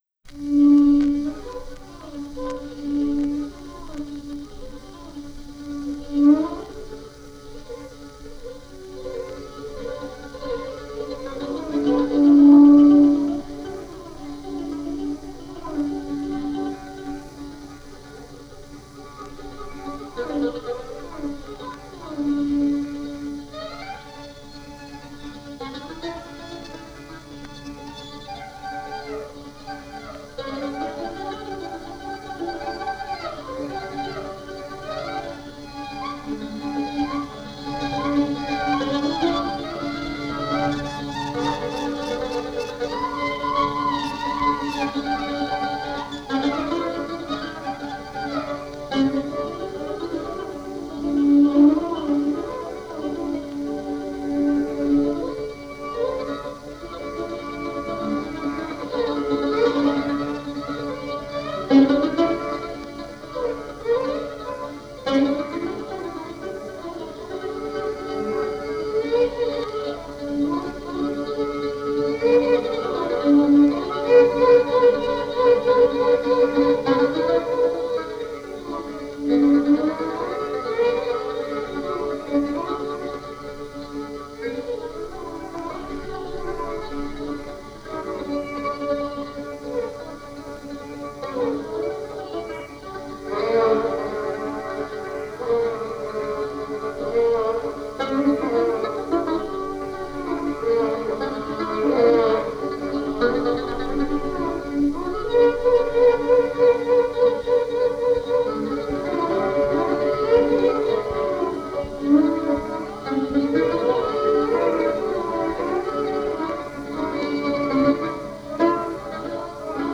Kaba e tipit dy pjesëshe: “E qarë-kaba” dhe pas saj valle me saze. “E qara” vjen tërësisht e strukturuar si vajtim me violinë, e mbushur me një sërë elementesh që ta kujtojnë vajtimin, sidomos rrëshqitjet në glisando dhe kadencimet në septima ngjitëse.
Rolin e prerjes e luan buzuku. Në pjesën e dytë motivi i bukur i valles vjen i qetë dhe i përbashkuar me timbret e violinës dhe buzukut si “marrësa”.
Qartazi dallohet se kjo kaba i përket traditës së shkollës korçare të violinës popullore.